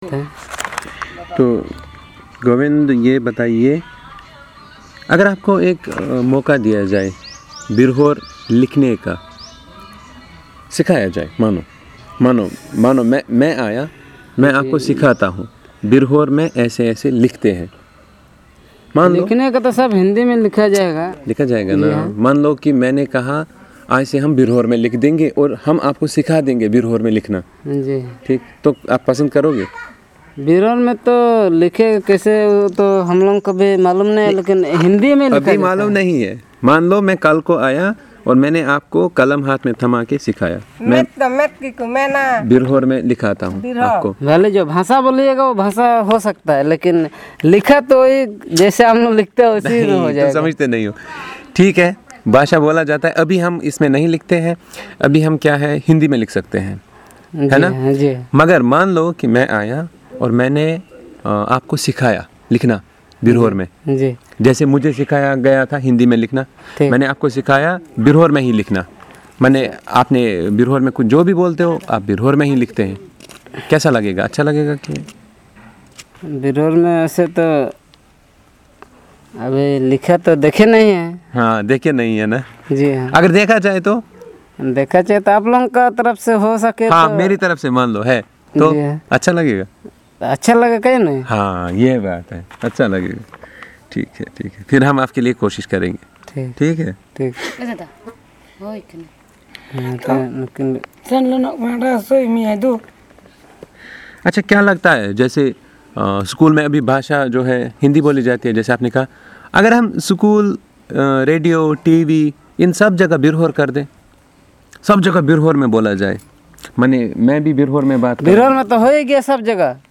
Discussion about language attitude and introduction of a new script for Birhor language